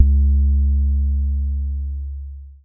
The Major 808.wav